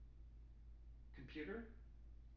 wake-word
tng-computer-380.wav